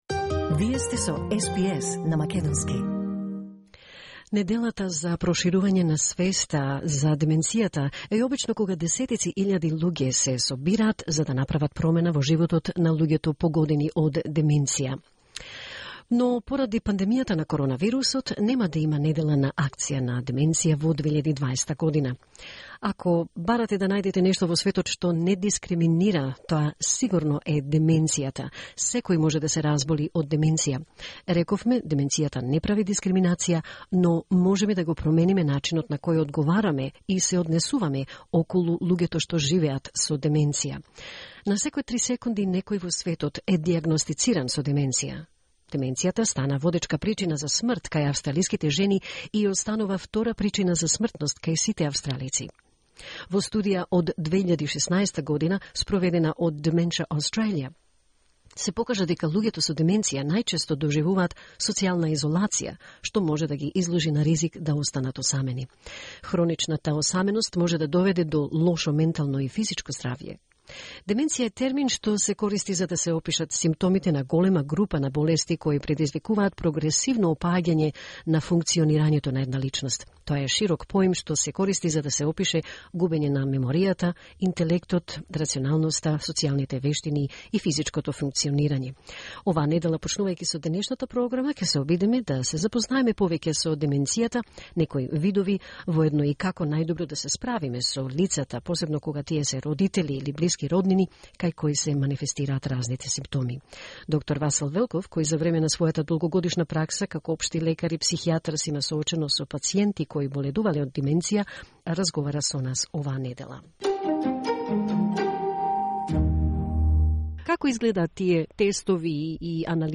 програмата на македонски јазик на СБС радио разговара со пензионираниот доктор